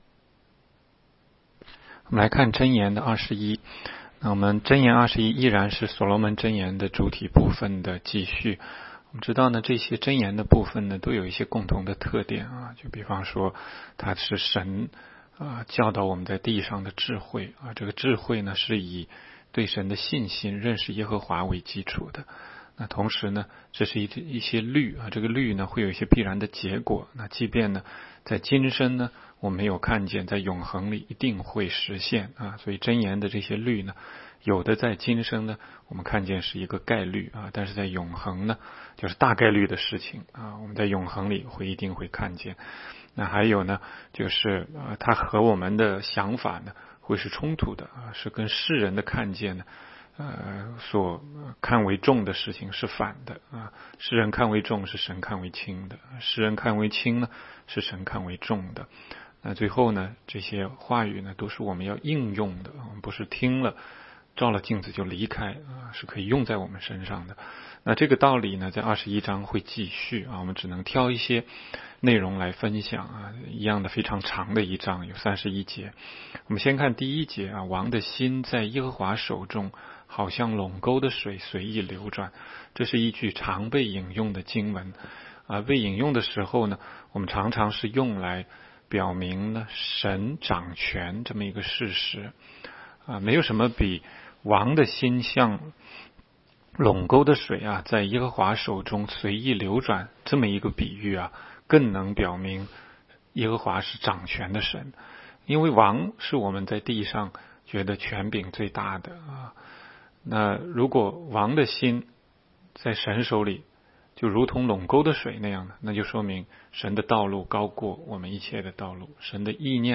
16街讲道录音 - 每日读经 -《 箴言》21章
每日读经